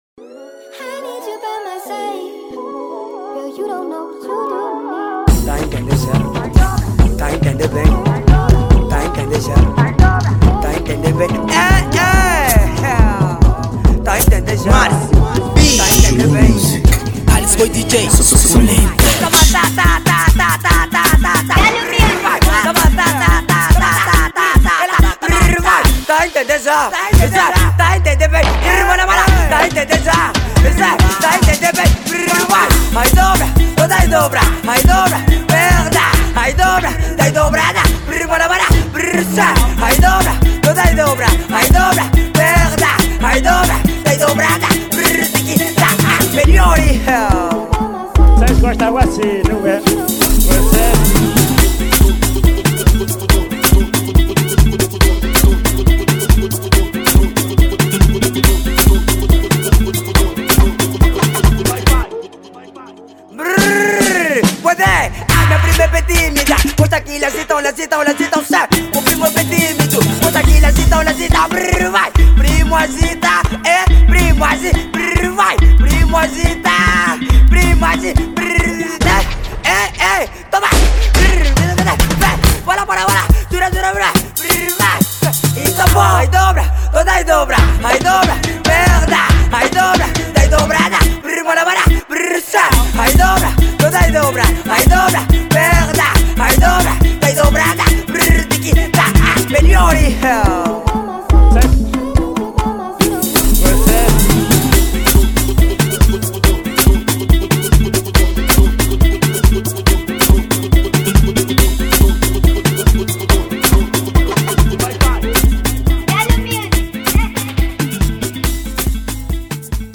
| Afro house